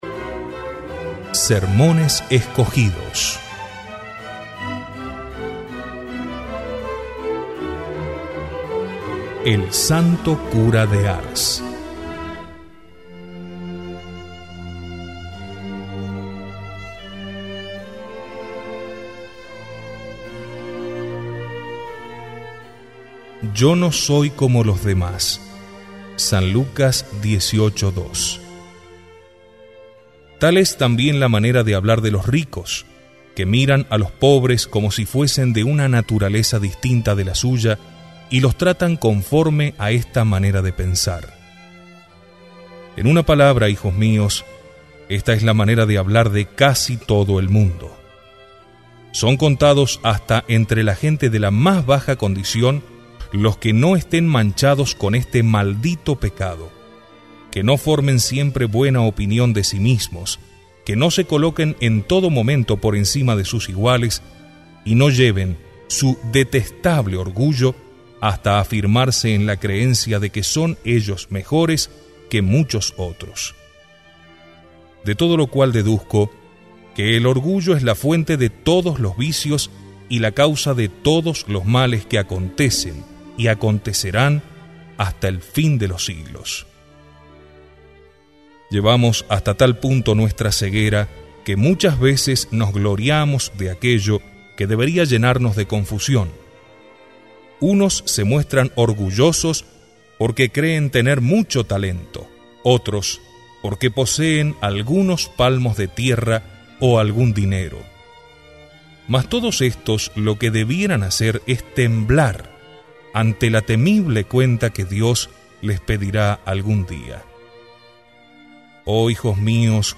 Audio–libros
Sermon-del-Santo-Cura-de-Ars-El orgullo.mp3